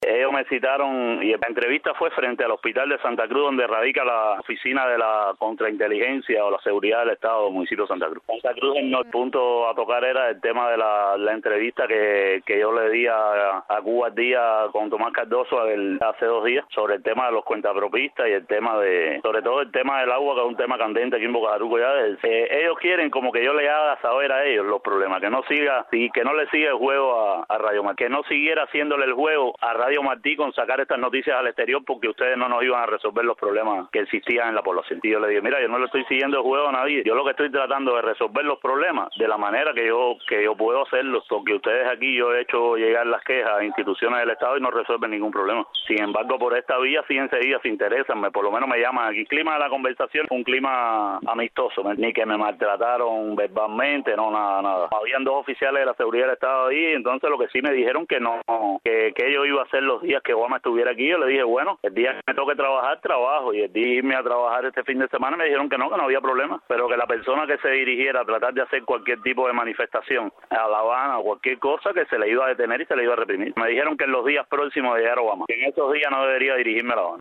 Al respecto este fue el testimonio que ofreció a Martí Noticias.